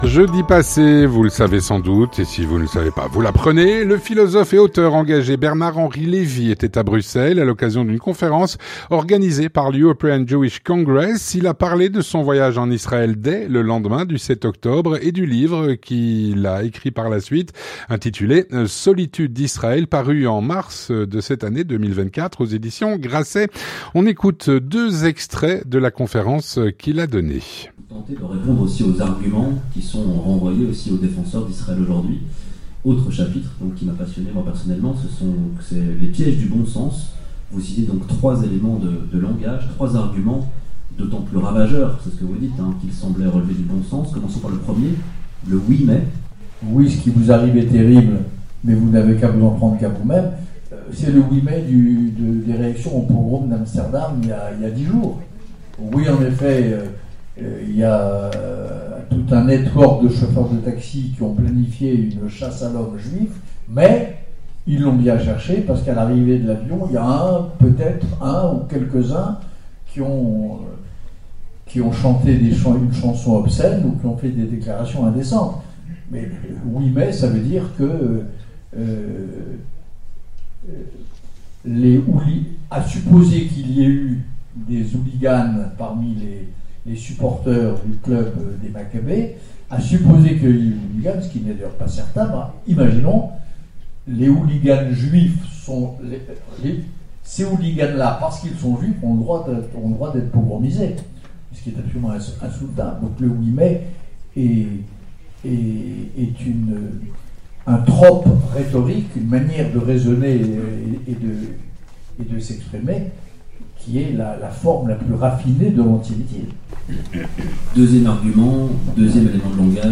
Jeudi dernier, le philosophe et auteur engagé Bernard Henri Lévy était à Bruxelles, à l’occasion d’une conférence organisée par l’European Jewish Congress.
Il y a parlé de son voyage en Israël, dès le lendemain du 7 Octobre, et du livre qui en a découlé : “Solitude d’Israël” paru en mars 2024 aux éditions Grasset. On en écoute des extraits.